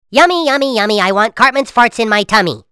Farts Pt2 Sound Effect Free Download
Farts Pt2